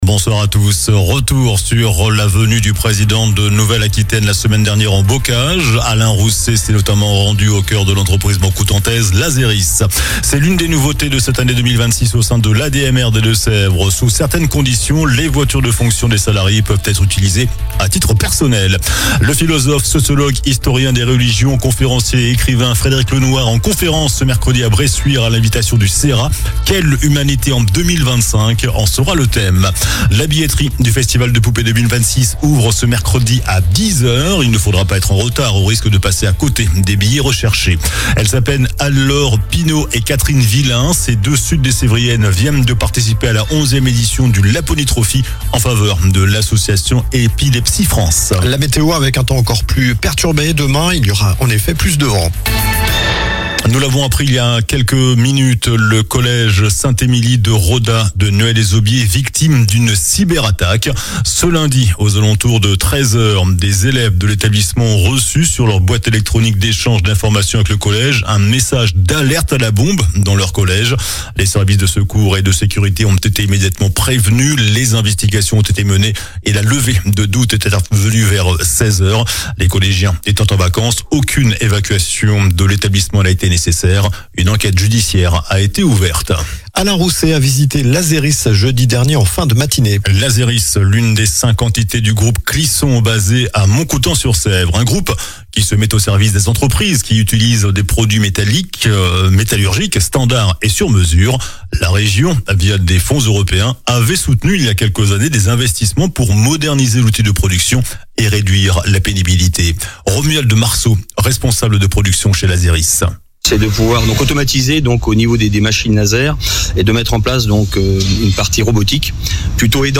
JOURNAL DU LUNDI 09 FEVRIER ( SOIR )